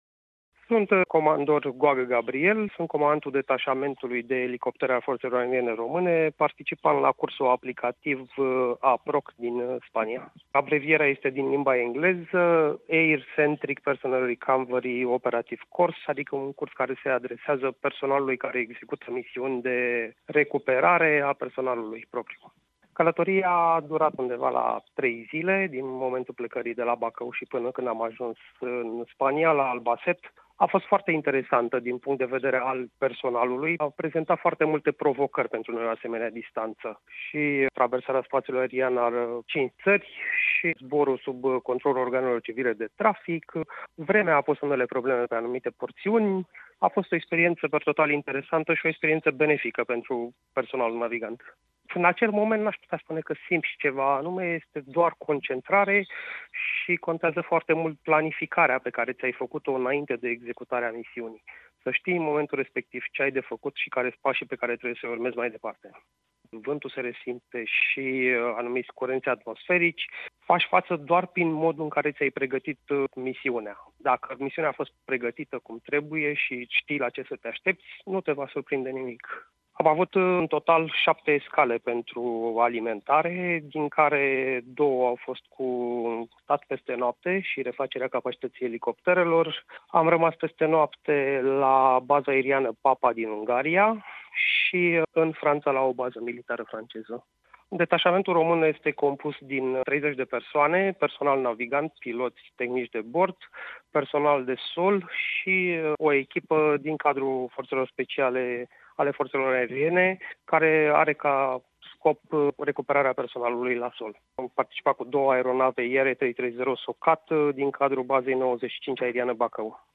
Cum s-a derulat acest stagiu de instruire din Spania, cum a fost drumul până acolo și cum s-au descurcat tinerii piloți ne-a împărtășit unul dintre participanții la acest eveniment.